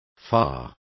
Complete with pronunciation of the translation of furthermost.